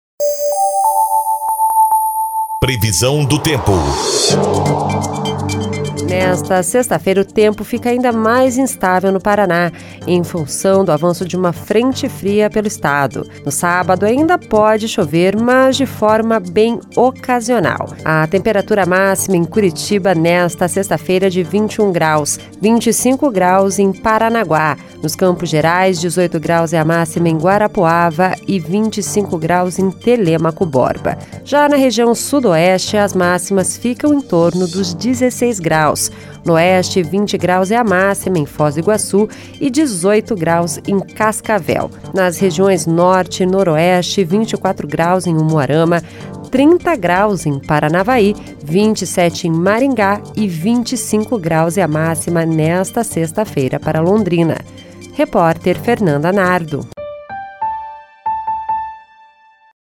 Previsão do Tempo (22/04)